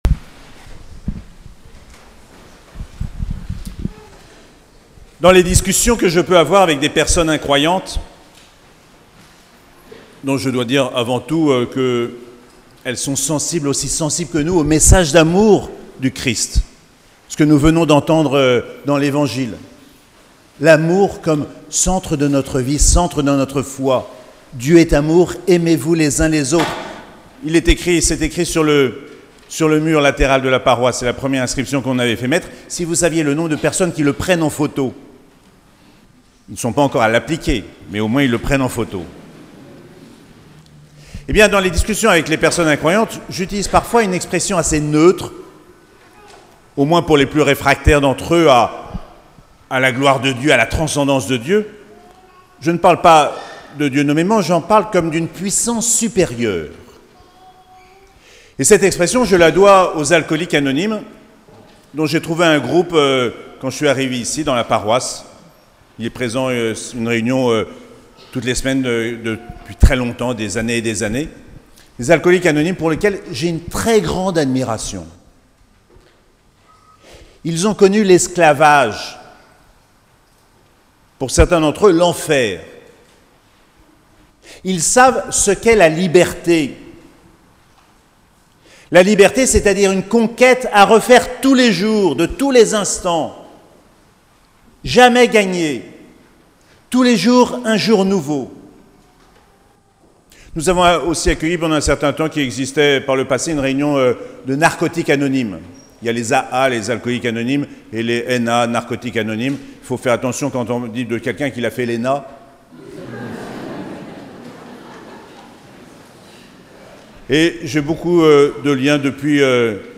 5ème dimanche de Pâques - 15 mai 2022